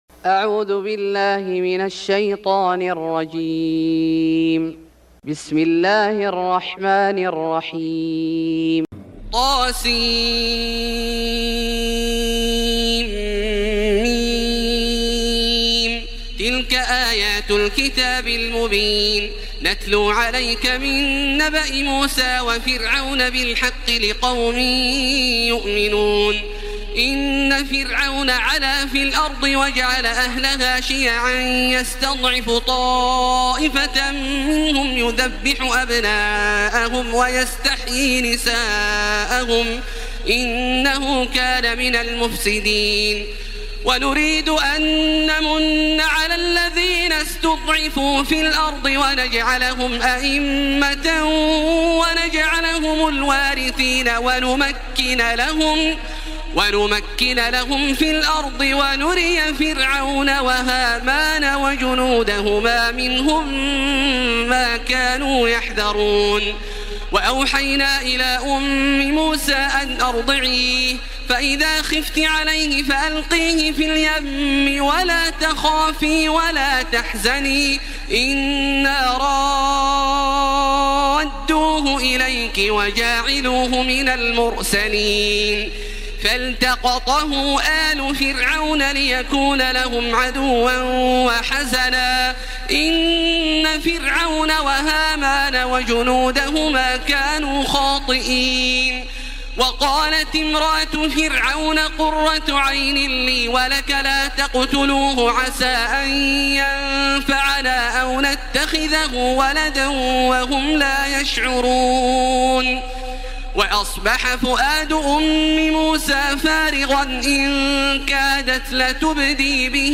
سورة القصص Surat Al-Qasas > مصحف الشيخ عبدالله الجهني من الحرم المكي > المصحف - تلاوات الحرمين